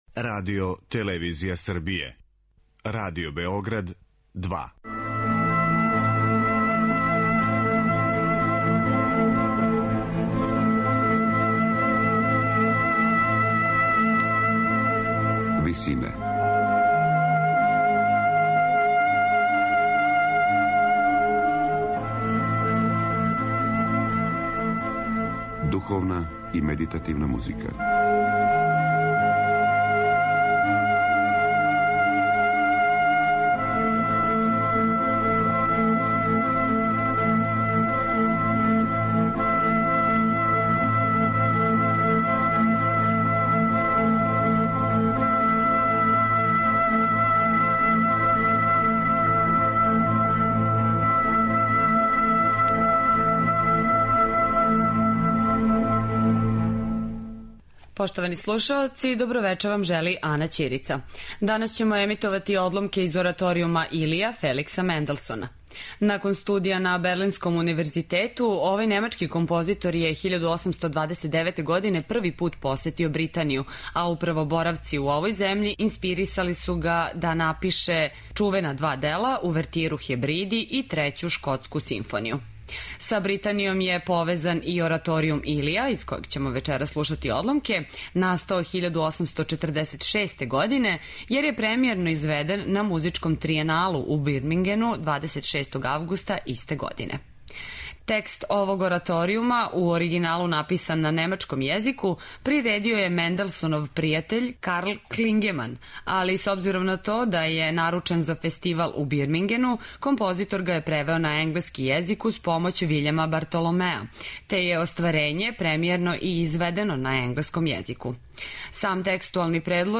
Ораторијум 'Илија'
медитативне и духовне композиције